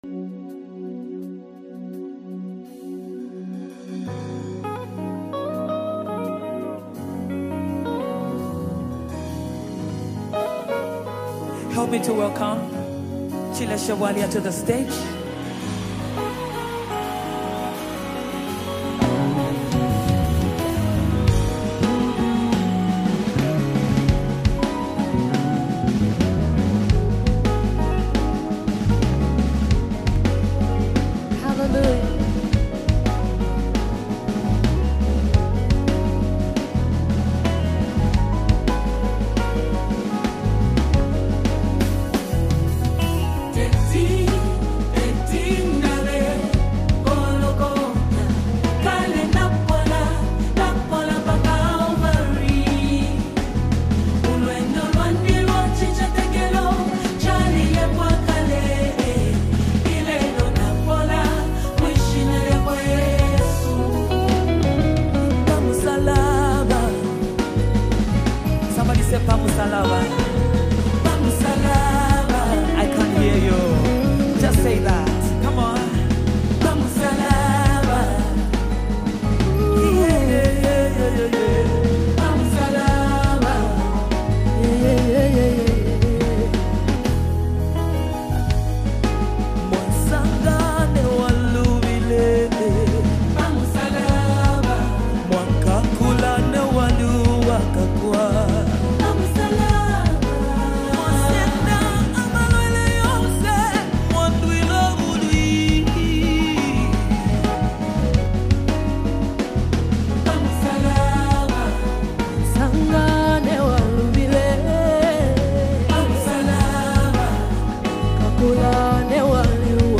Powerful Zambian Gospel Collaboration 2025
soft, passionate vocals